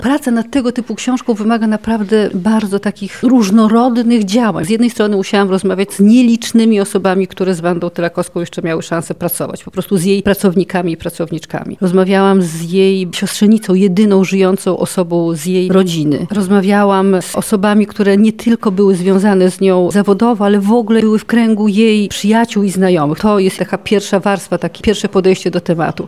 Wydarzenie odbyło się w galerii LubVintage.